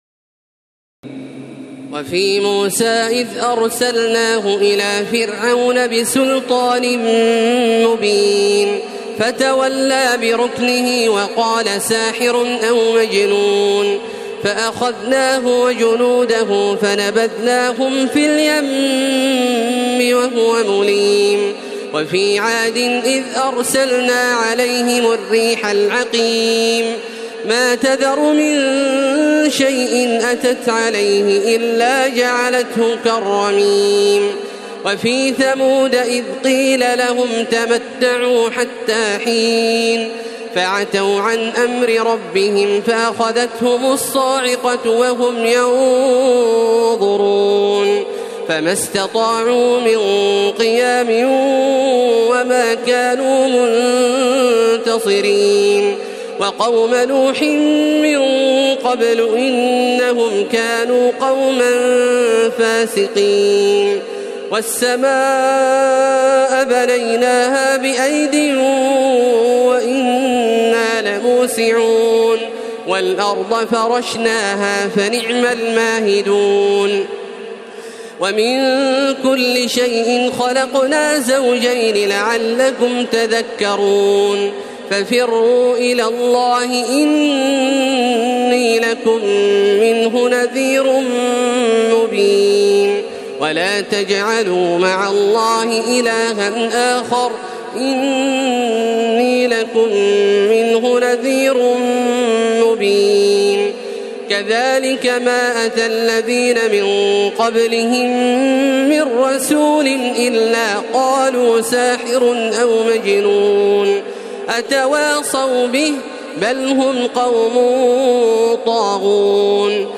تراويح ليلة 26 رمضان 1432هـ من سور الذاريات(38-60) و الطور و النجم و القمر Taraweeh 26 st night Ramadan 1432H from Surah Adh-Dhaariyat and At-Tur and An-Najm and Al-Qamar > تراويح الحرم المكي عام 1432 🕋 > التراويح - تلاوات الحرمين